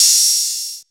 • Open Hat F Key 27.wav
Royality free open high-hat tuned to the F note. Loudest frequency: 6539Hz
open-hat-f-key-27-4z3.wav